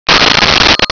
Blip
BLIP.WAV